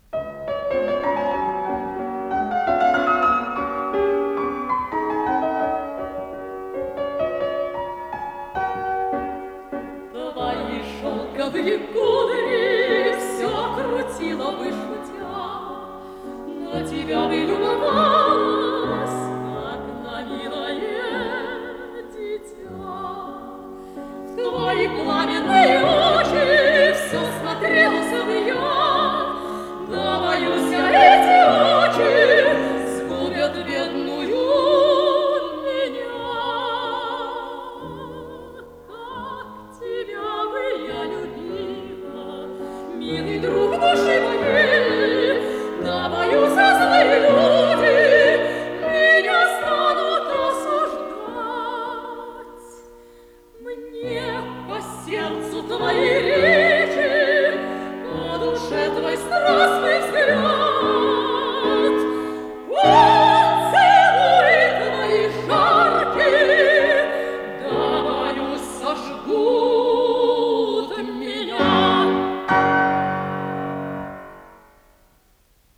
с профессиональной магнитной ленты
сопрано
меццо-сопрано
фортепиано
ВариантДубль моно